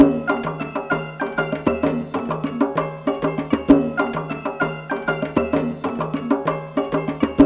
Index of /90_sSampleCDs/Zero G - Ethnic/Partition F/ZITHERLOOPS2
ZITHALOOP7-L.wav